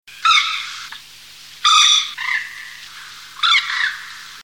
Gallinule poule d'eau
Gallinula chloropus